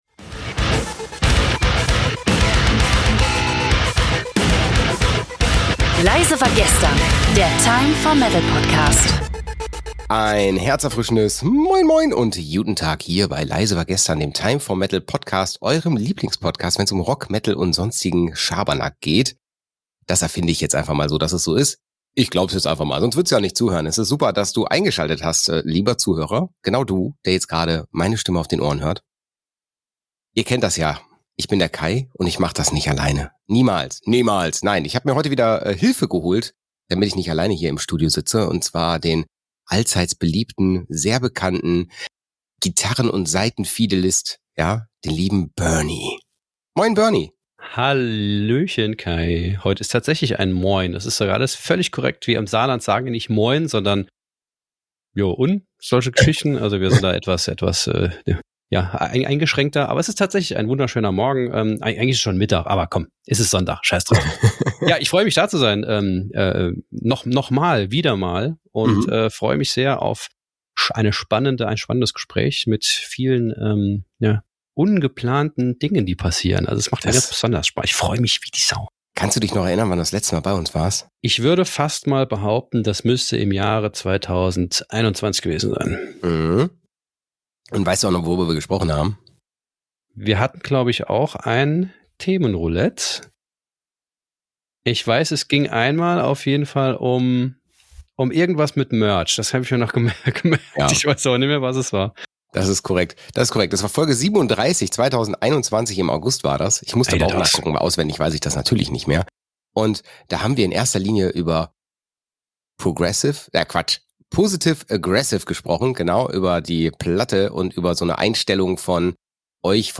Wir werden euch mit exklusiven Interviews, tiefgründigen Diskussionen und natürlich jeder Menge großartiger Musik versorgen.